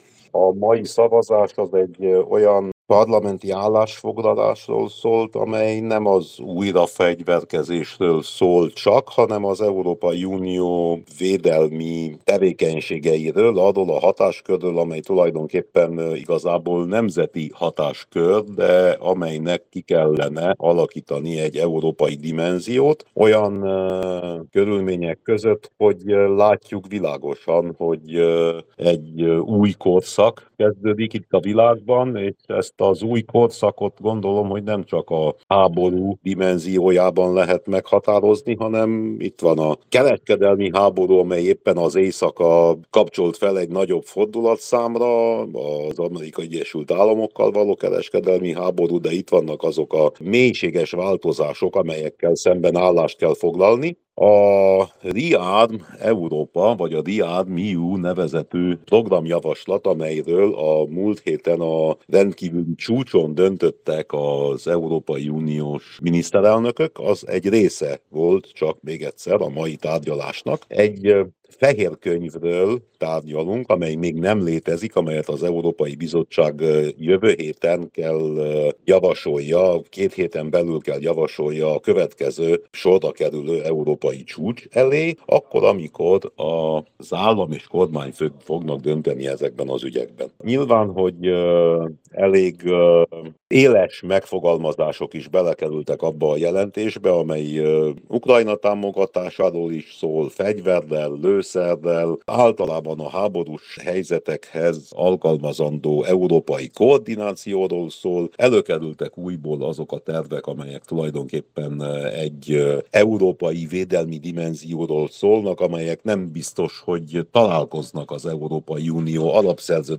mondta el Winkler Gyula RMDSZ-es EP-képviselő